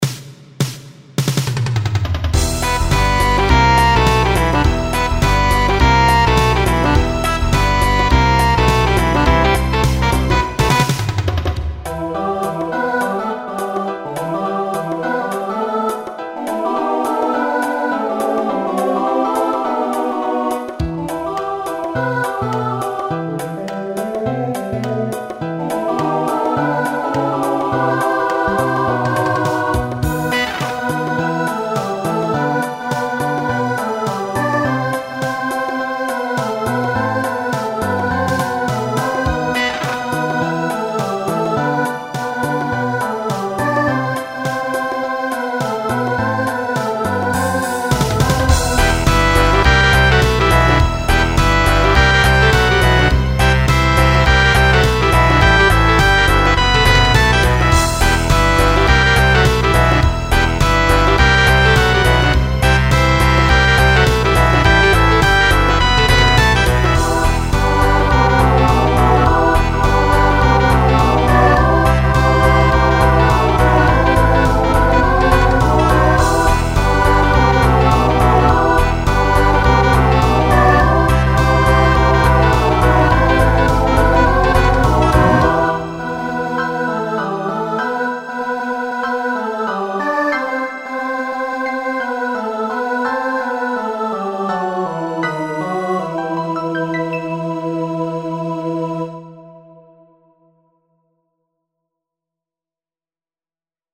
Rock , Swing/Jazz
Instrumental combo
Transition Voicing SATB